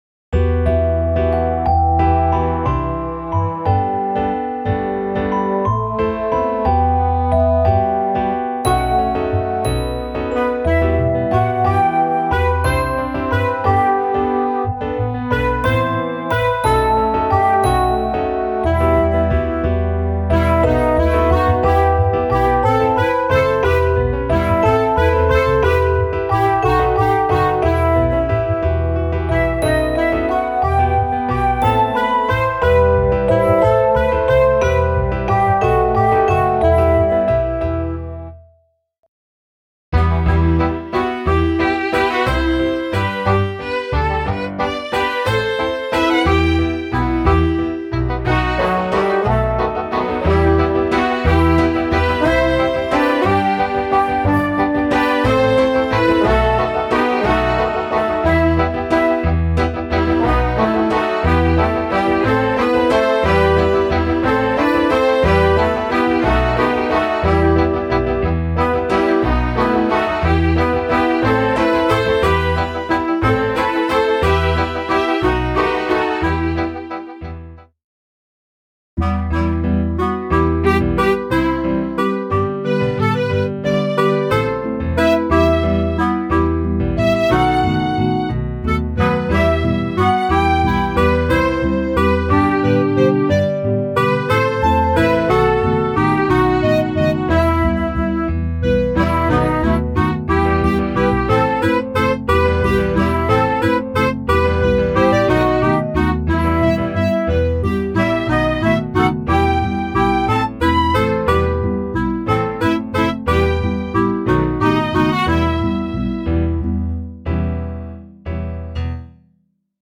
Instrumentalsätze